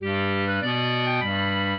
clarinet